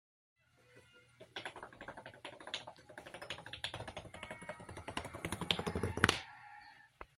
Soothing Sound Of Dominoes Falling... Sound Effects Free Download
soothing sound of Dominoes falling... sound effects free download